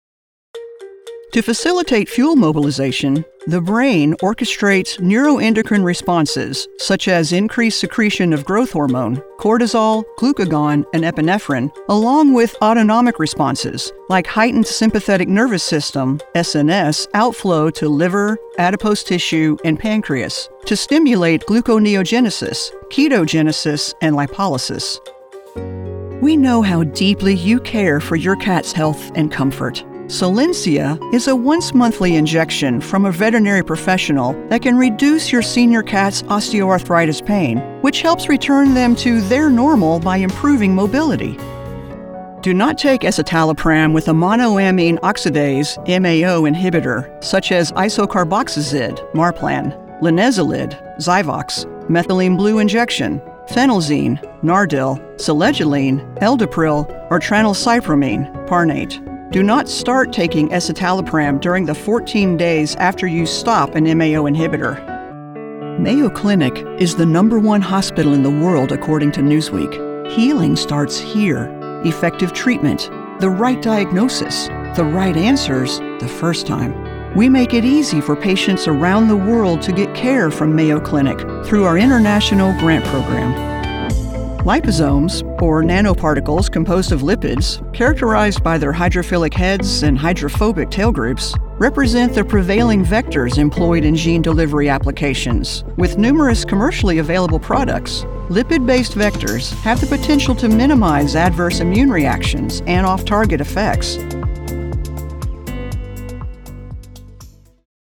Medical Demo
English - Southern U.S. English
Appalachian, Southern Georgia coast
Middle Aged